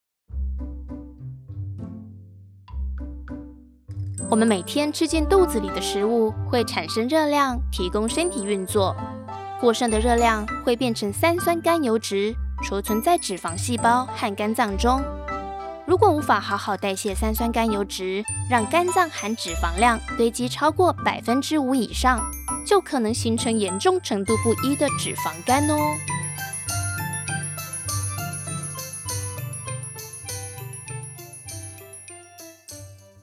I am a Taiwanese Mandarin speaker.
I am a young and mature female vocal artist with experience in various areas of voice over work.